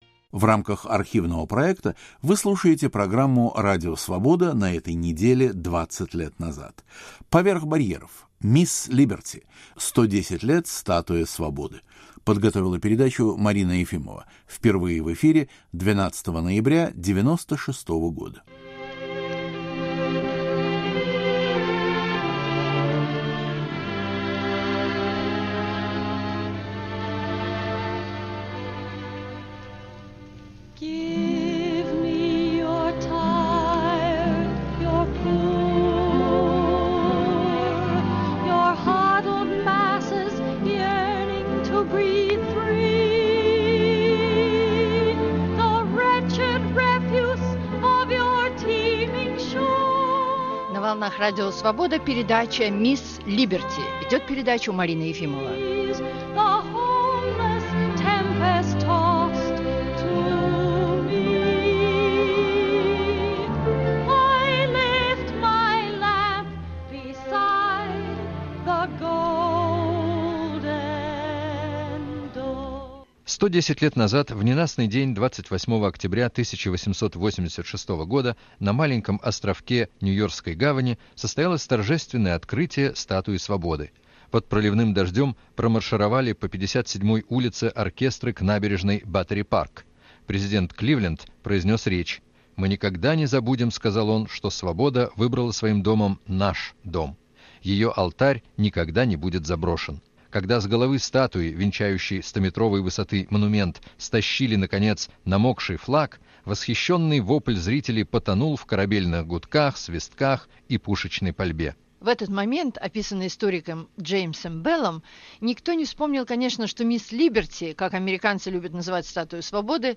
посетители острова Либерти. Впервые в эфире 12 ноября 1996.